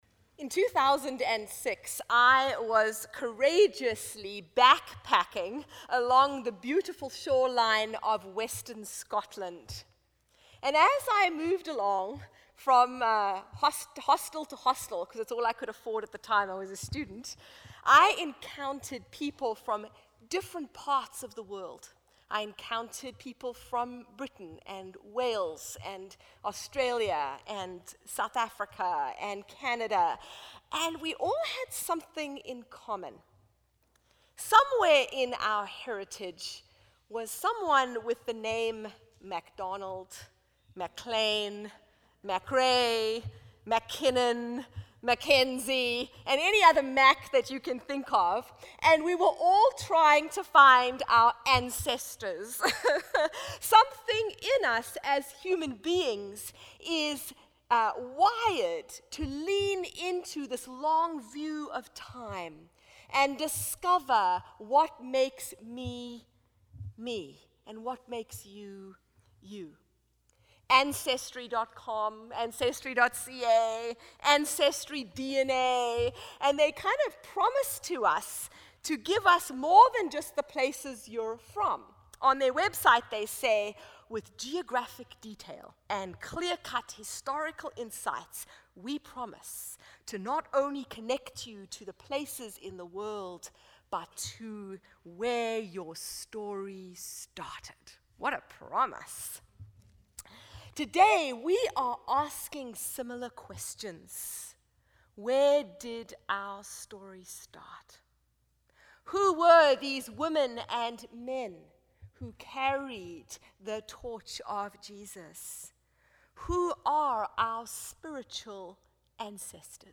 Download Download Reference Luke 6: 20-31 Sermon Notes For all the Saints 2019.pdf Who are saints in your family of faith?